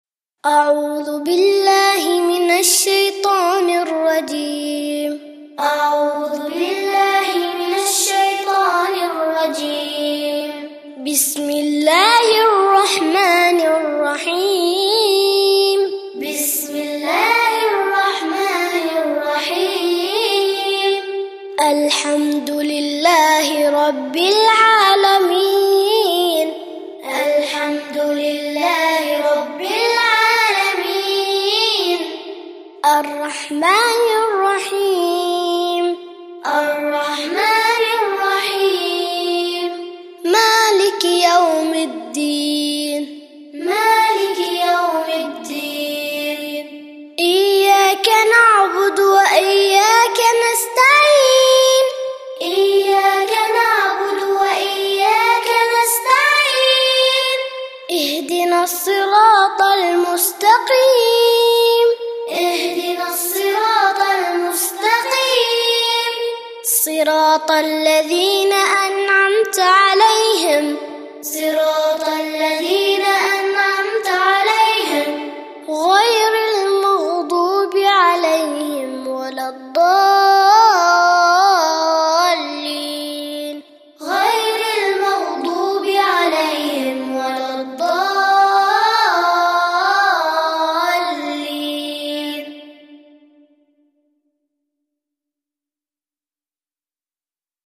الفاتحة (تعليمي للصغار) - لحفظ الملف في مجلد خاص اضغط بالزر الأيمن هنا ثم اختر (حفظ الهدف باسم - Save Target As) واختر المكان المناسب